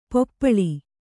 ♪ poppaḷi